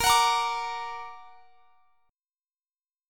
A#+ Chord (page 4)
Listen to A#+ strummed